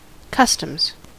Ääntäminen
Ääntäminen US Tuntematon aksentti: IPA : /ˈkʌstəmz/ Lyhenteet ja supistumat (laki) Cust.